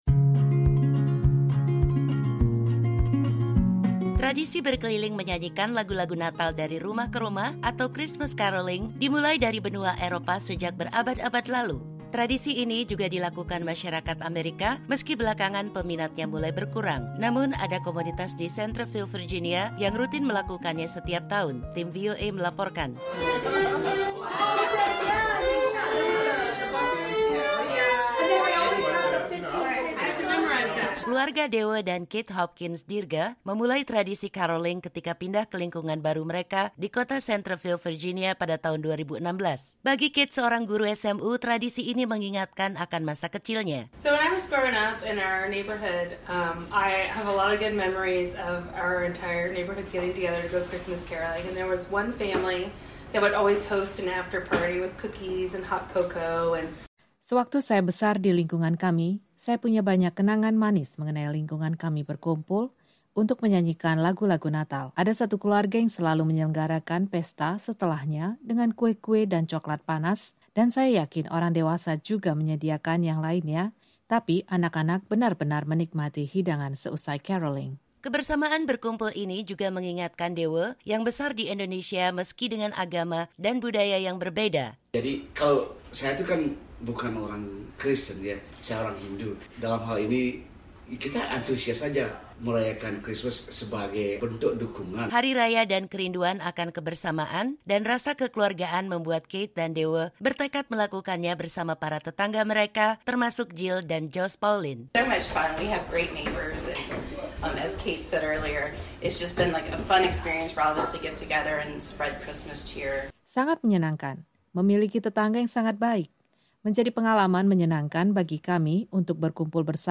Caroling dimulai ketika matahari sudah tenggelam di tengah cuaca yang cukup bersahabat pada musim dingin.
Peserta mengetuk pintu-pintu rumah tetangga yang dengan suka cita mendengarkan lagu-lagu natal.